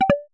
notification_sounds / down.mp3